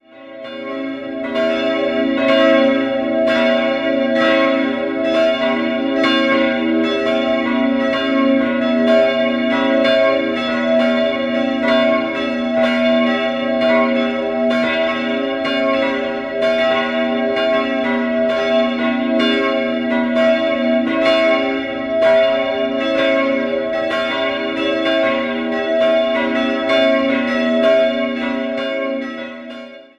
3-stimmiges Gloria-Geläute: h'-cis''-e'' Die große Glocke stammt aus der Nürnberger Gießhütte (zweite Hälfte des 15. Jahrhunderts), die beiden kleineren wurden 1973 von der Glockengießerei Heidelberg gegossen.